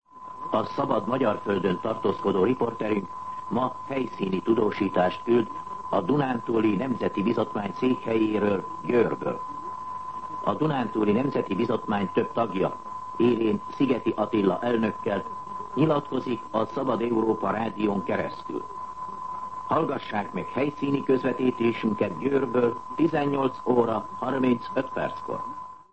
Műsorajánló